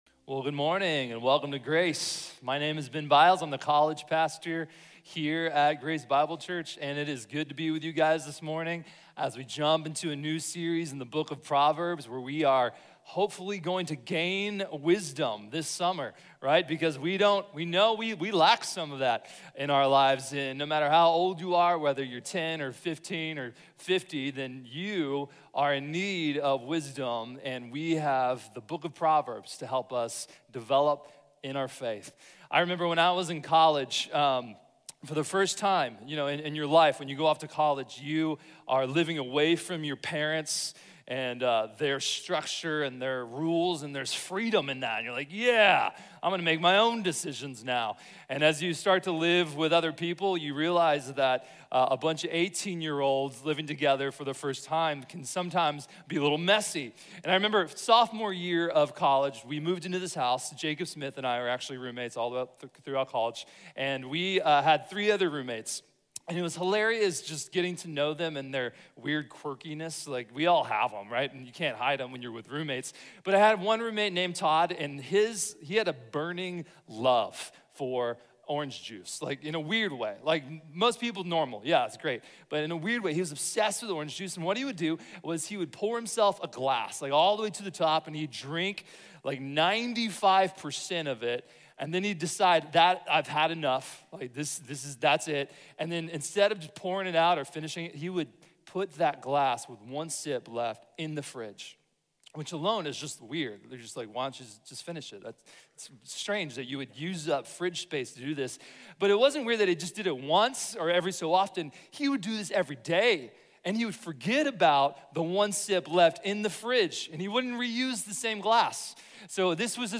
The Wisely-Lived Life | Sermon | Grace Bible Church